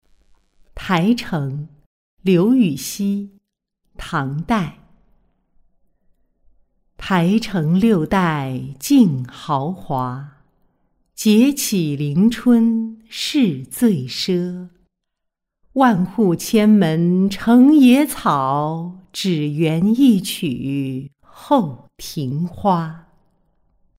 金陵五题·台城-音频朗读